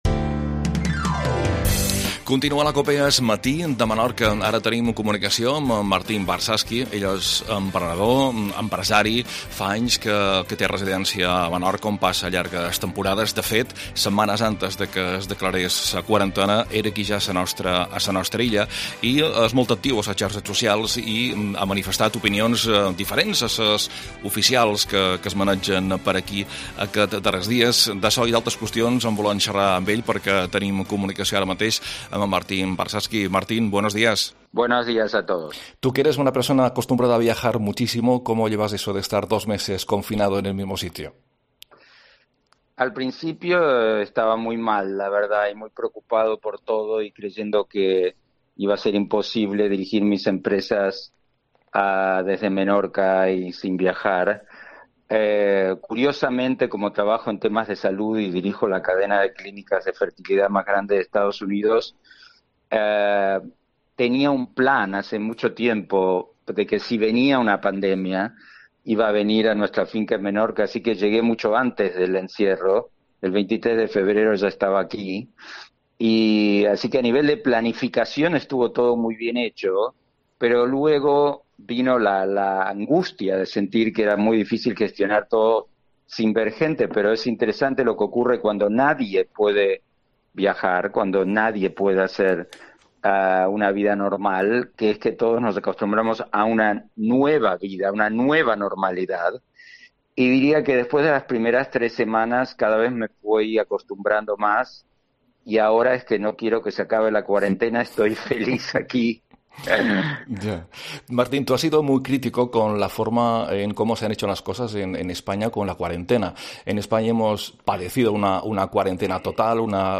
AUDIO: Entrevista a Martin Varsavsky